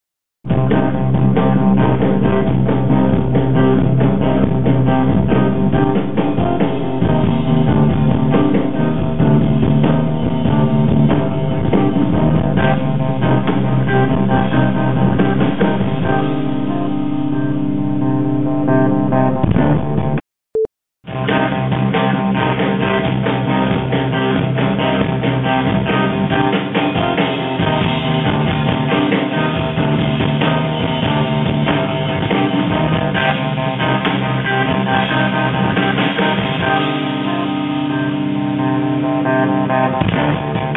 Very muffled audio from concert recording; can it be fixed?
Point-and-shoot cameras just don’t have the capability of handling the extreme high sound levels from live rock concerts.
The sample rate on that is only 8000Hz, so sounds very muddy, (no frequencies above 4KHz).
{ Update: listening back I think I’ve used a bit of chorus on the treble boosted version, gives a stereo effect .